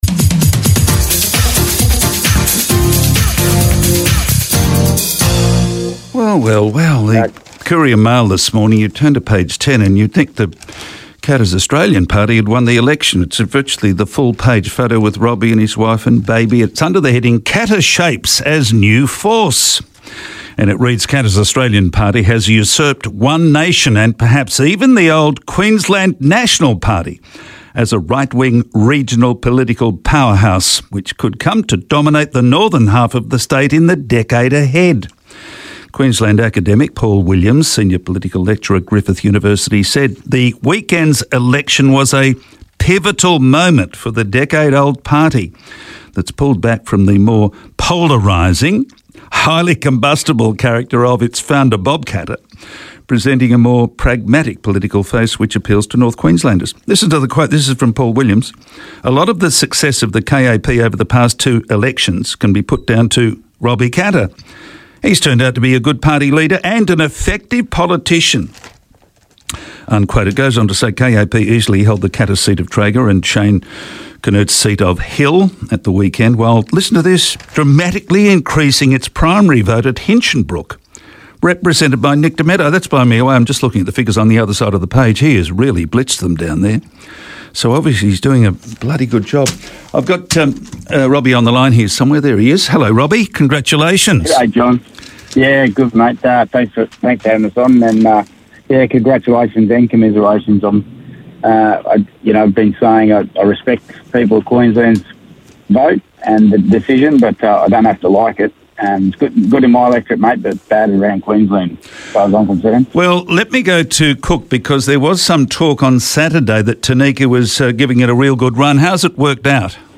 Today I spoke to Robbie Katter, the Queensland State Leader of Katter’s Australian Party, about the outcome from the weekend's State Election.